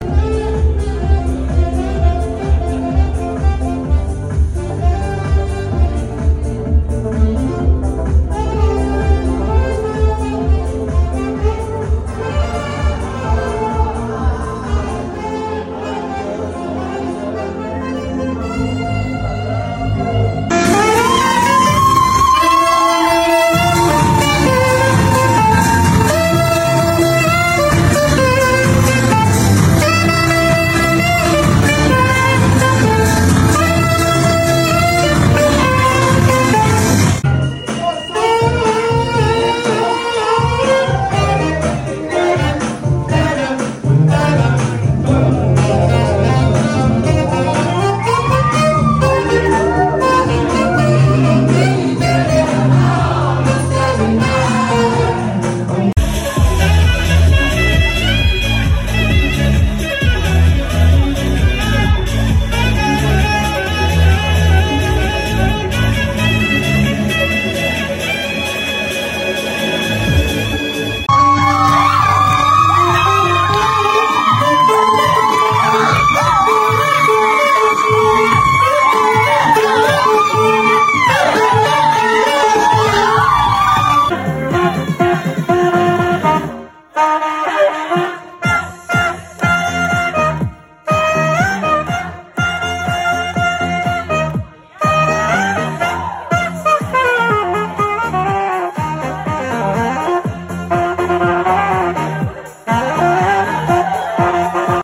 Fun & interactive party saxophonist to get guests dancing!